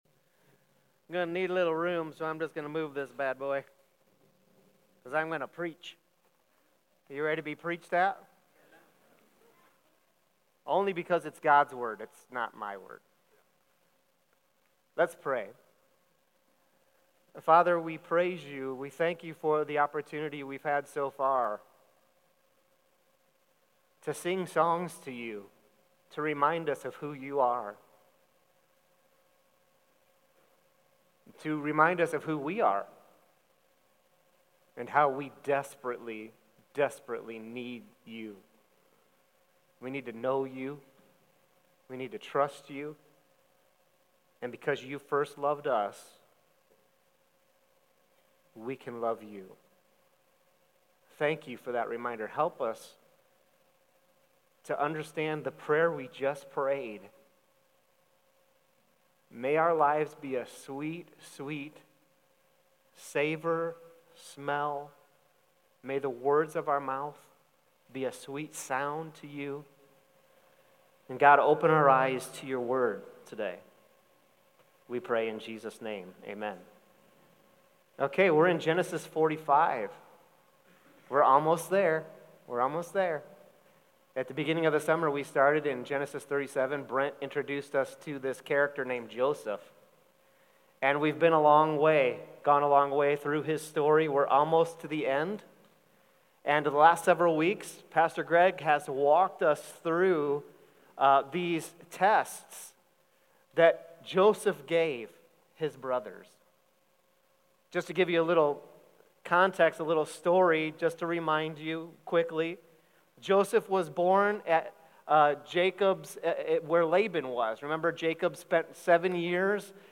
Sermon Questions: Read Genesis 45:1–15 (focus on verses 5–9).